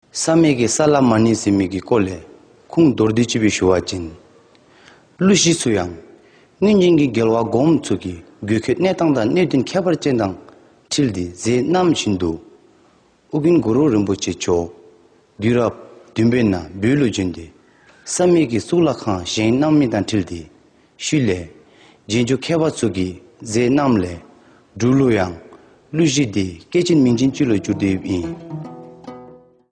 Here’s a recording in a mystery language.